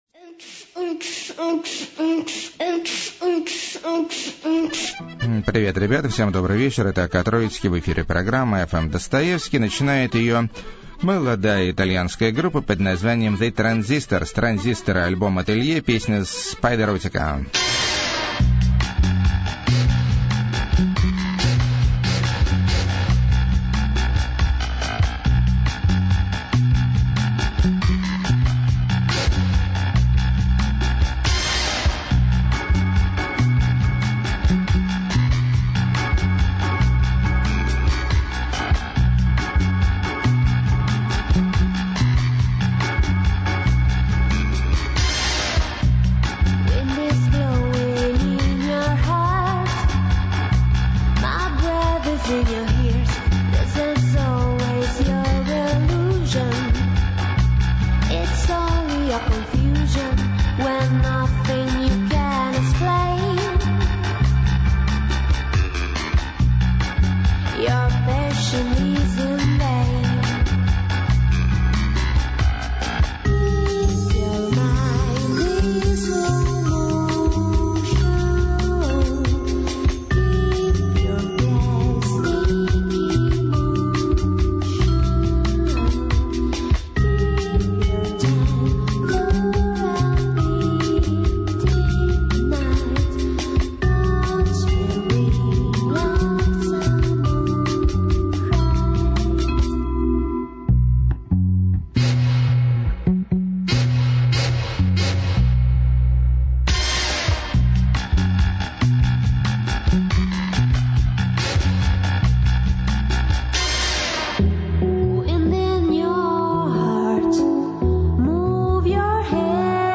Organola Miniskirt Swing
Ghosts Dancing Foxtrot
Runic Big Beat
Ukelele Kitsch Weirdness
Blues In The Garage
Gloomy Neo Folk
Downtempo Fellinesque